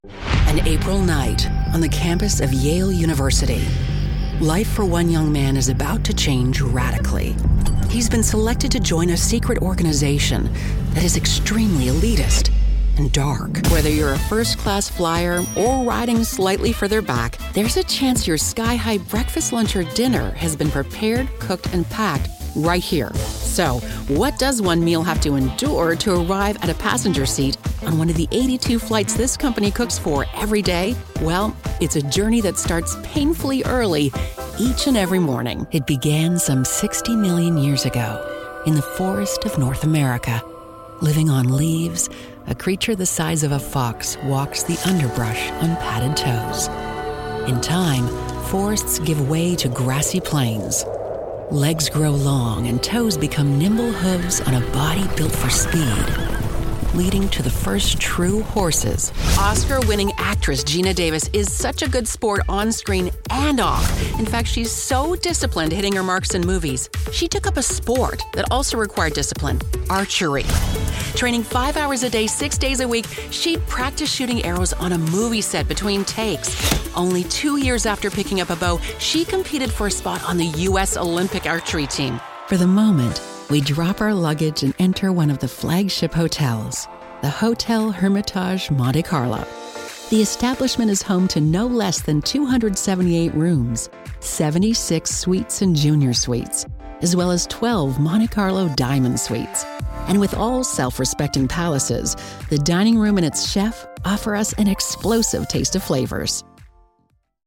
Englisch (US)
Dokumentarfilme
Eine warme, fürsorgliche Stimme für mitfühlende Werbespots.
Mit einer StudioBricks-Kabine und einem Sennheiser-Mikrofon produziere ich effektiv Audio in Studioqualität.